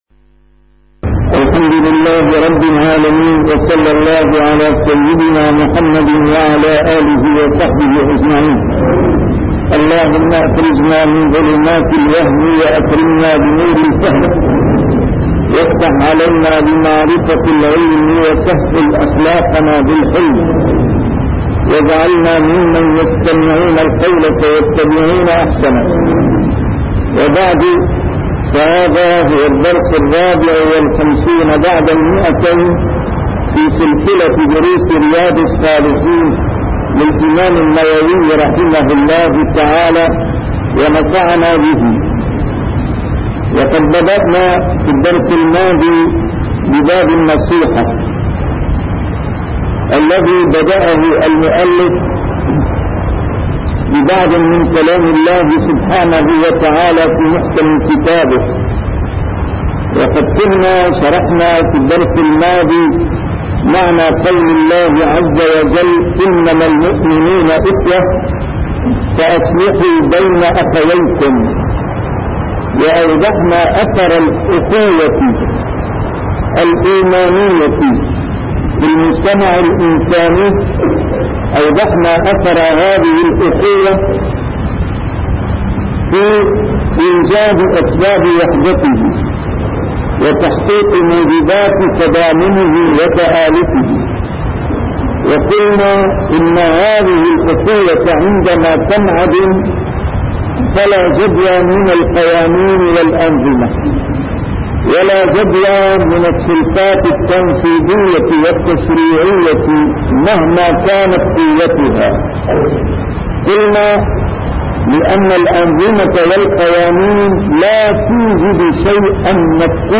A MARTYR SCHOLAR: IMAM MUHAMMAD SAEED RAMADAN AL-BOUTI - الدروس العلمية - شرح كتاب رياض الصالحين - 254- شرح رياض الصالحين: النصيحة